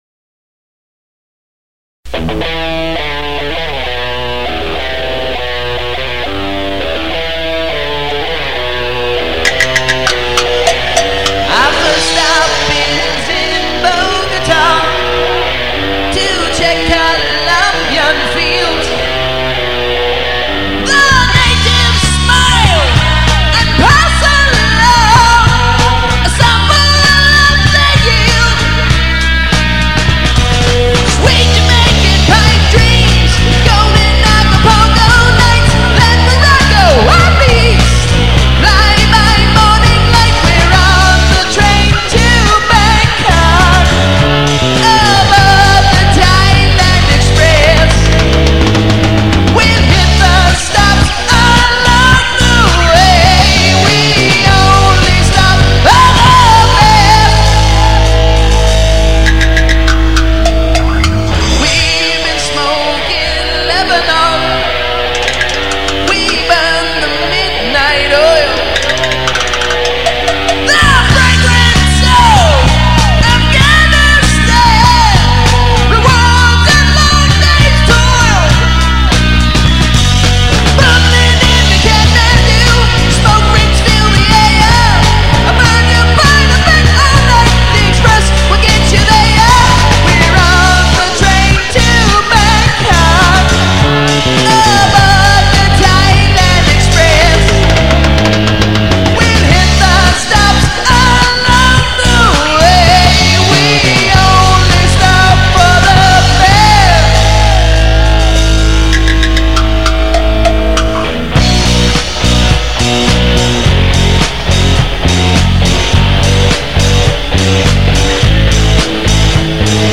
Recorded at Mid-South Audio in Georgetown DE